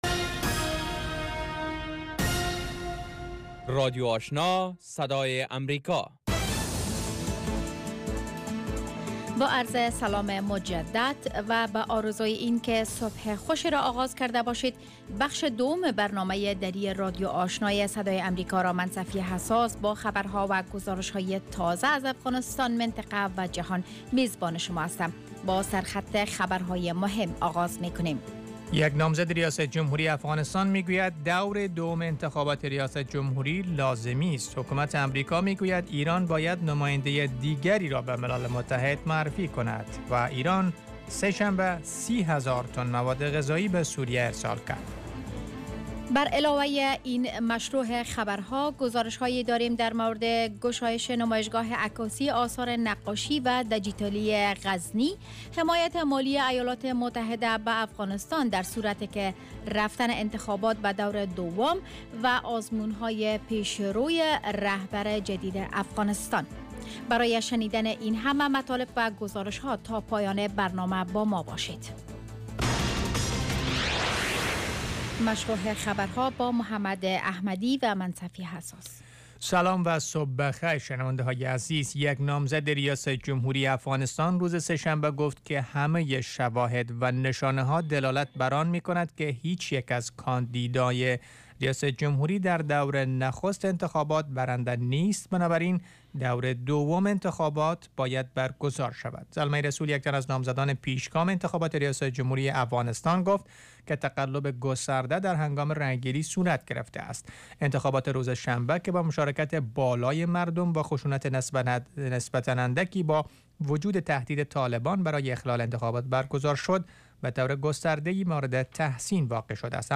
morning news show second part